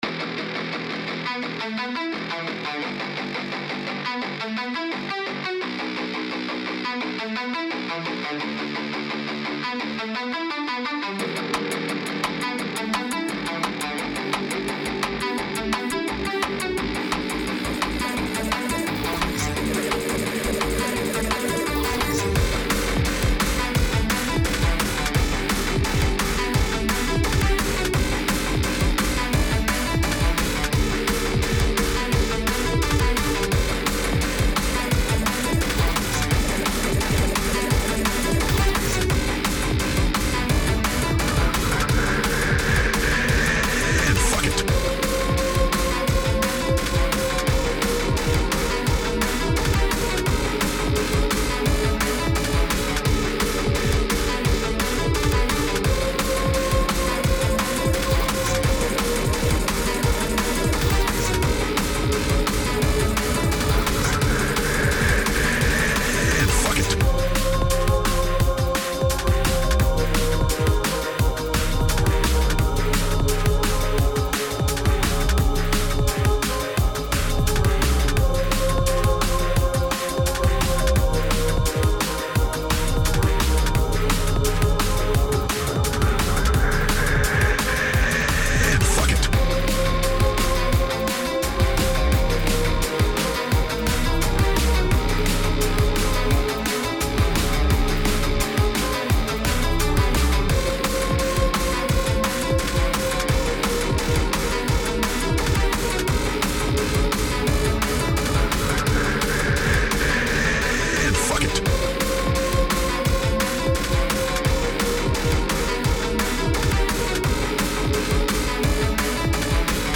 Tempo 172BPM (Vivace)
Genre Gritty Drum and Bass
Type Vocal Music
Mood Conflicting (Aggressive/energetic)